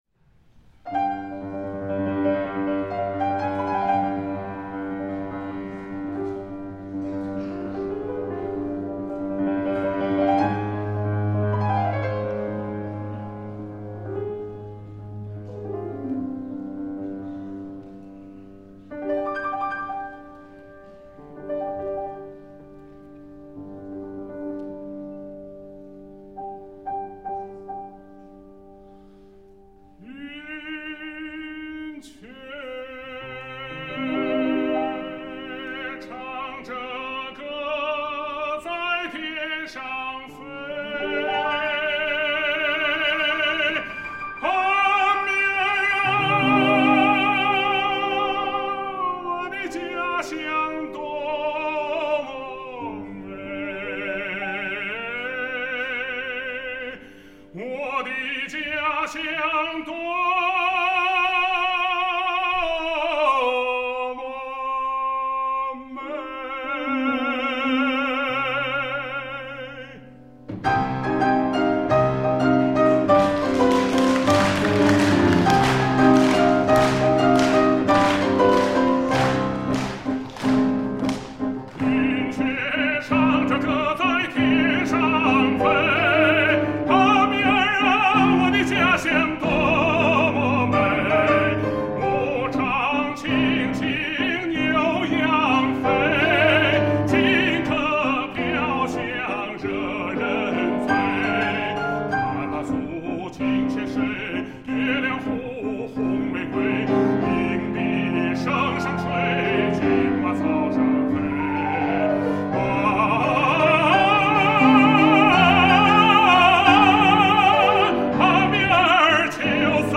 现场演唱难免有瑕疵，前面都唱得挺好。结果结尾那些弯弯儿遛得不太到位，出了小问题。